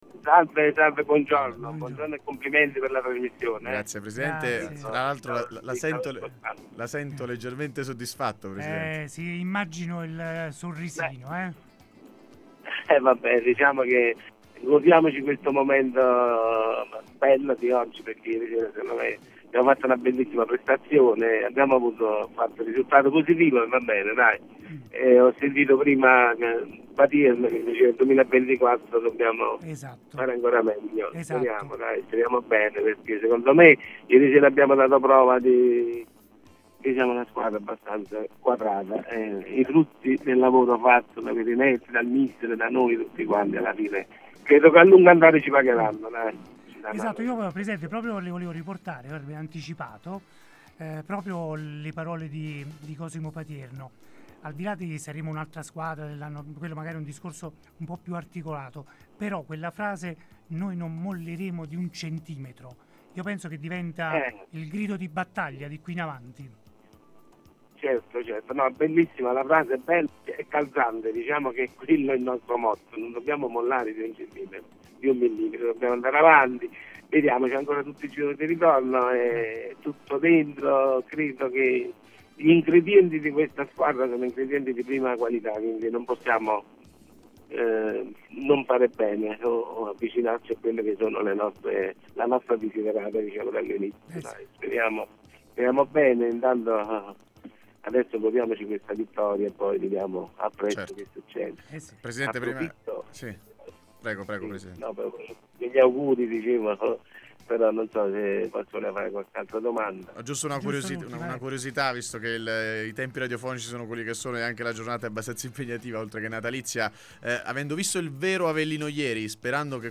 L’intervento a sorpresa a Radio Punto Nuovo in occasione delle festività natalizie.